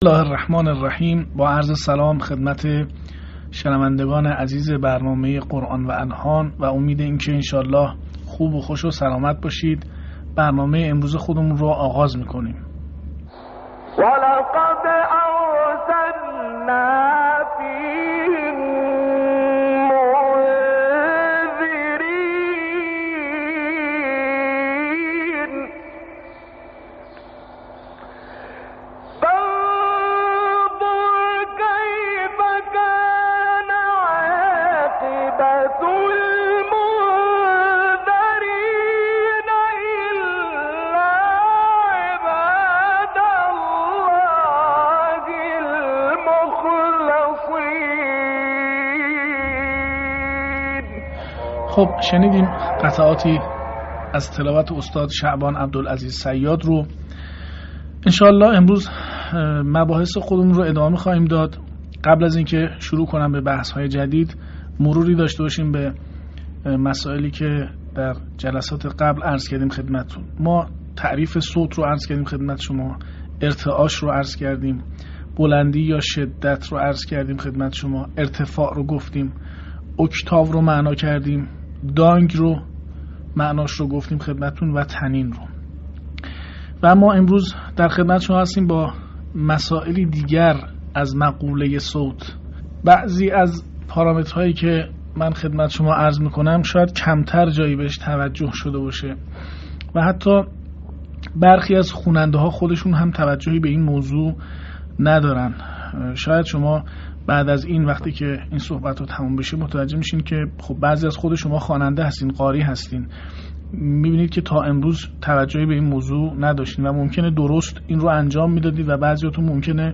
صوت | آشنایی با «سرعتِ صوت» هنگام تلاوت قرآن
آموزش صوت و لحن قسمت هفدهم